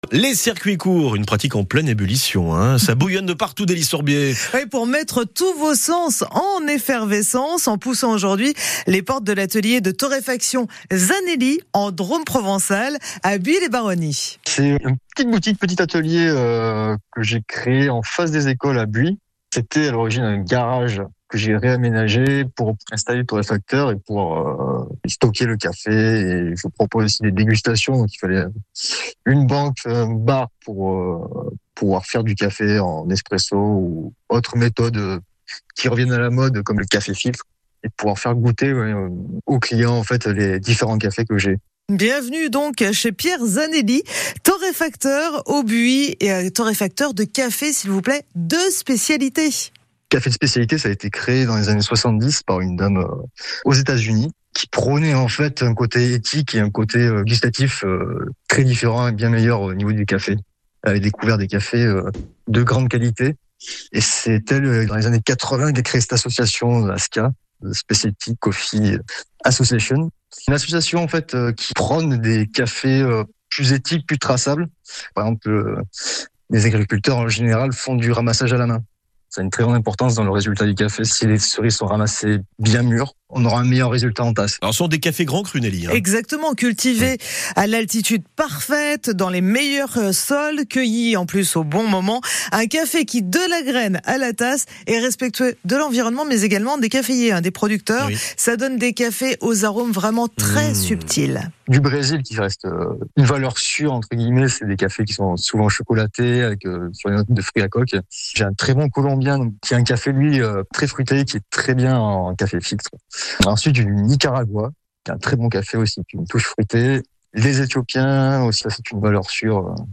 On parle de nous à la radio !!!